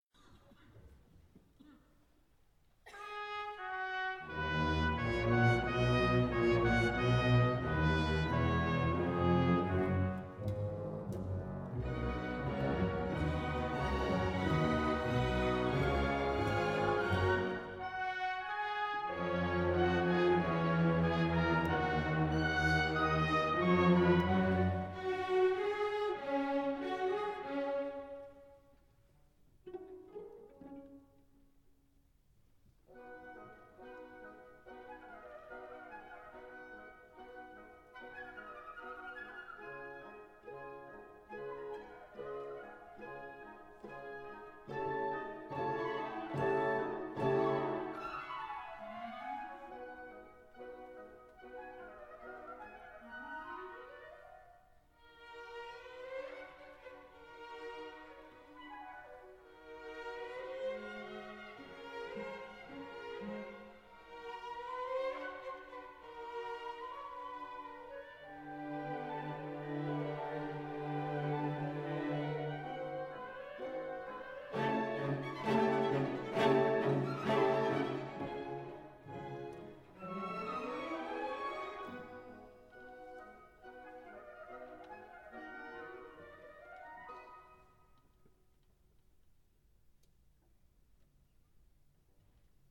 National Concert Hall, Dublin. 3rd November 2015.